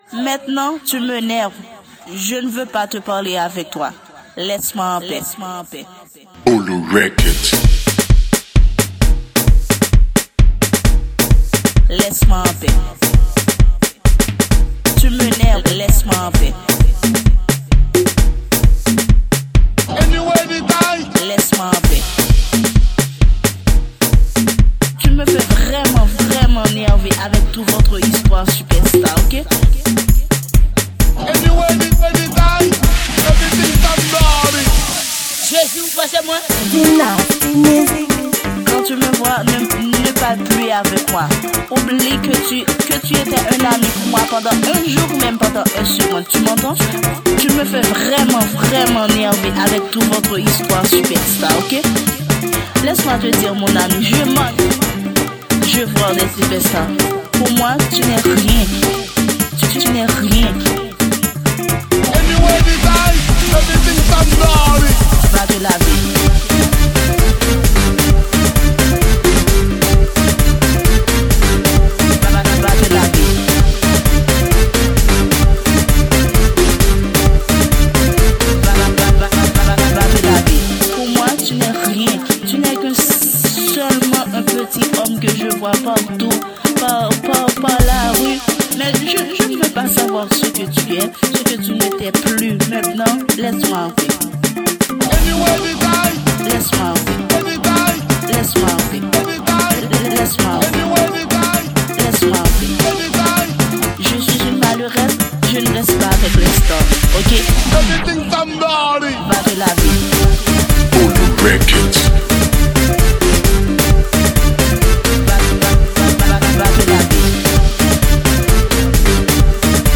Genre: Mix.